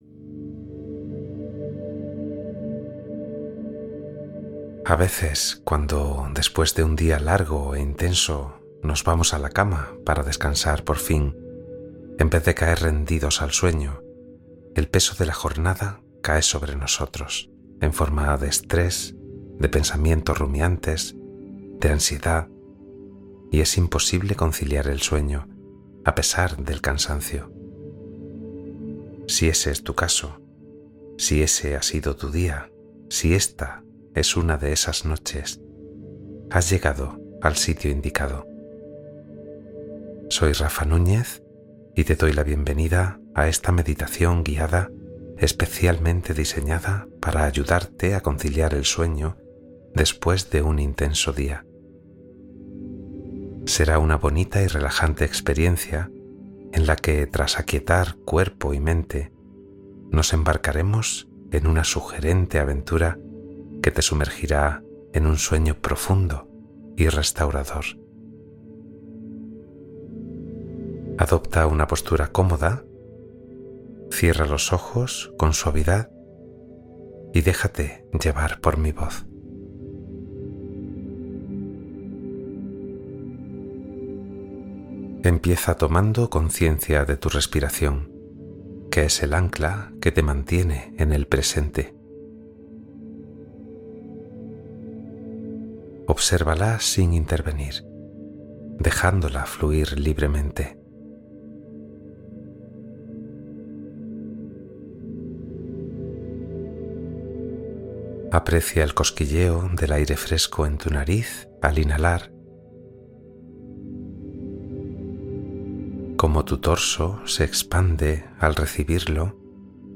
Relajación Guiada para Dormir y Renovarte